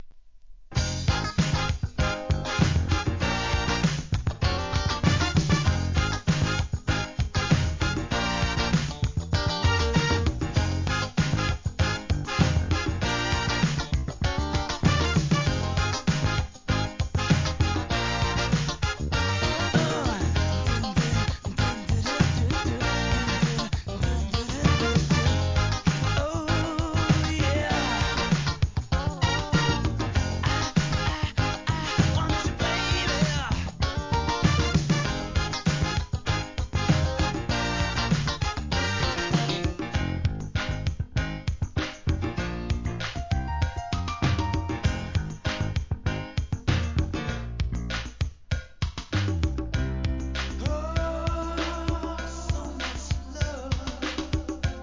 SOUL/FUNK/etc...
ホーンが追加されたb/wがおすすめ。